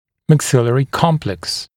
[mæk’sɪlərɪ ‘kɔmpleks][мэк’силэри ‘комплэкс]комплекс верхней челюсти, верхнечелюстной комплекс